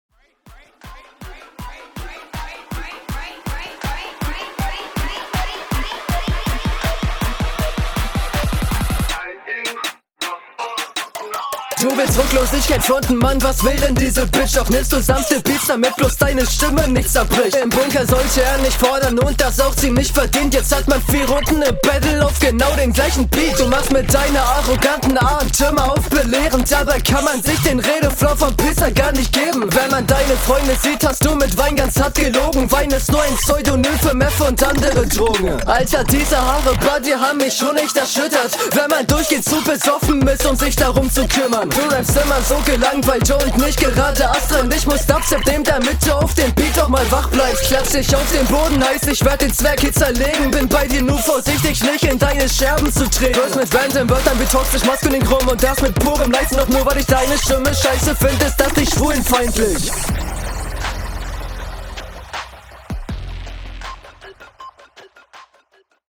Flow: schön druckvoll und alles im allem auch sehr nice, von den Sachen die ich …